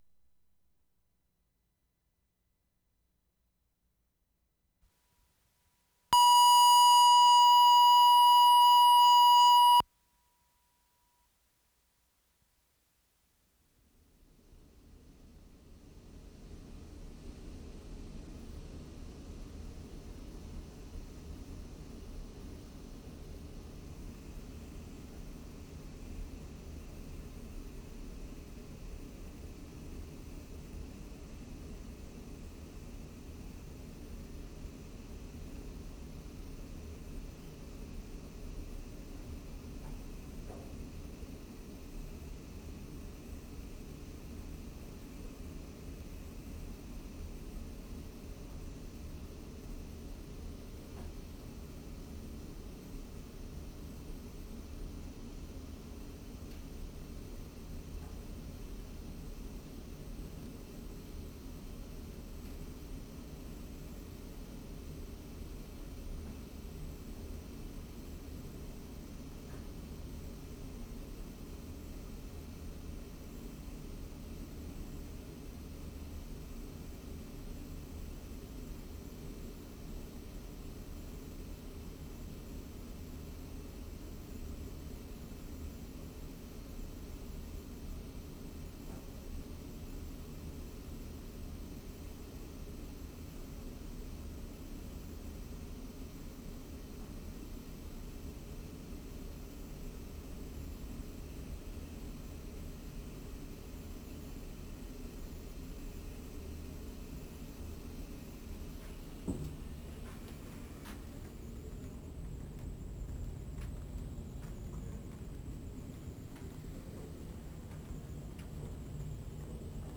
WORLD SOUNDSCAPE PROJECT TAPE LIBRARY
2. Short tour of study areas on the 5th floor with whitewashed soundscape, i.e., ventilation noise, air conditioning.
1'35" microphones move around periphery of stacks.